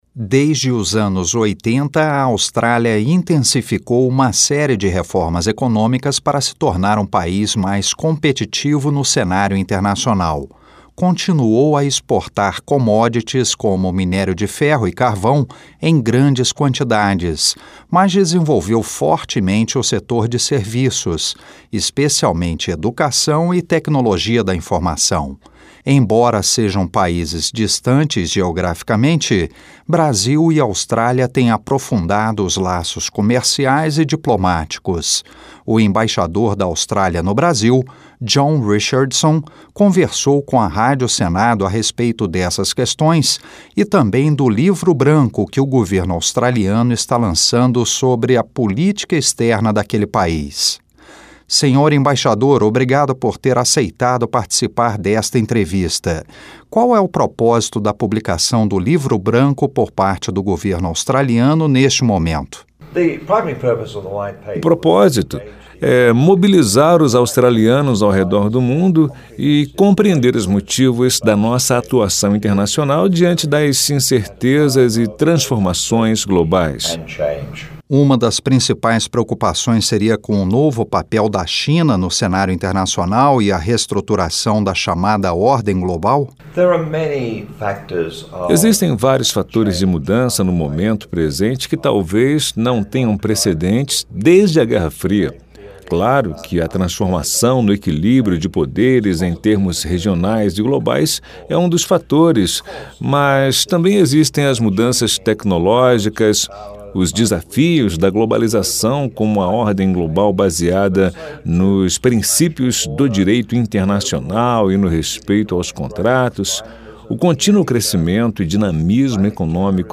Entrevista com o embaixador da Austrália no Brasil, John Richardson